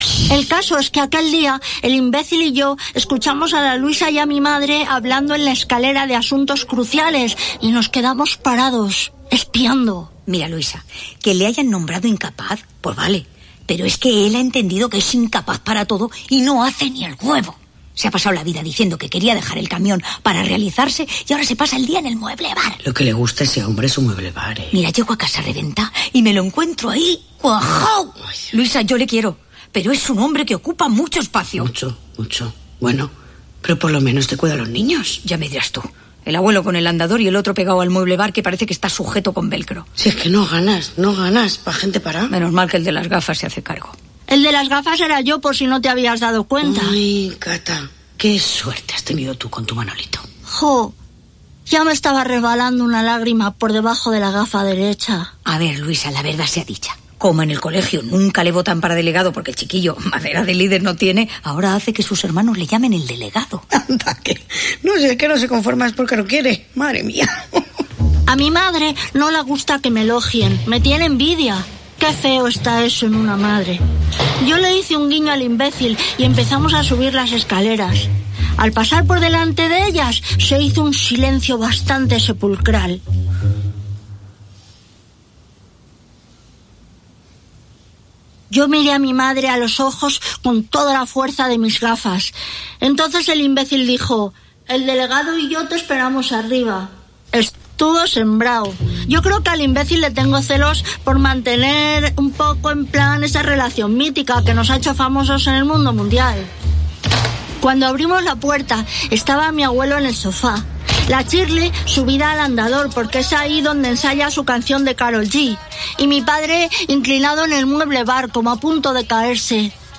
1 Hora L del 30/12/2024 1:00:00 Play Pause 16h ago 1:00:00 Play Pause Riproduci in seguito Riproduci in seguito Liste Like Like aggiunto 1:00:00 Magazine d'actualitat de la Catalunya Central amb entrevistes polítiques, socials i culturals. L'Hora L escolta les problemàtiques del territori, amb connexions d'actualitat i descobrint el patrimoni, la gastronomia i les festes de les comarques centrals.